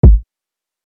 SOLD MY SOUL TO SATAN WAITING IN LINE IN THE MALL KICK.wav